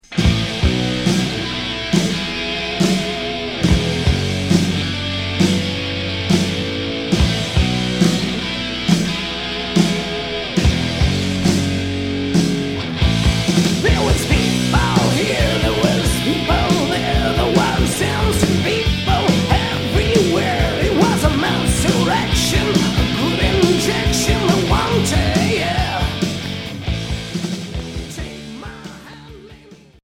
Hard heavy Premier 45t